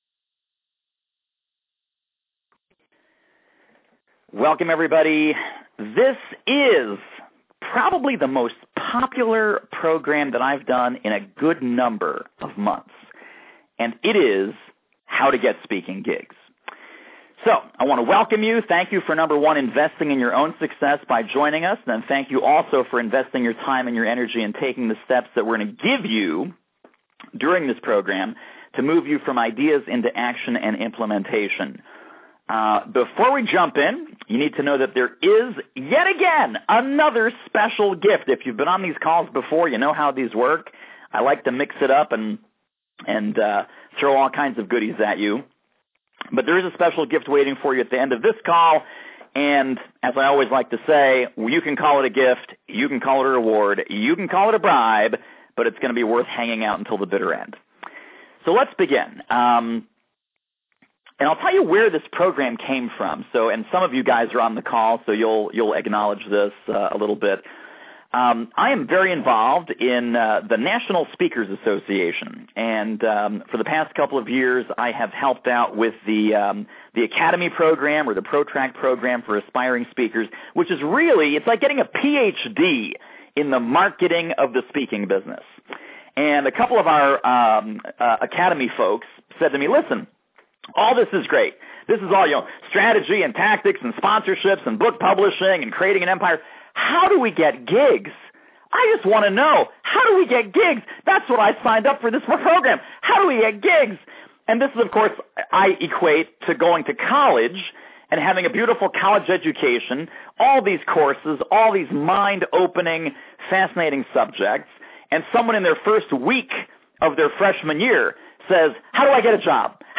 How to Get Speaking Gigs. FREE Teleseminar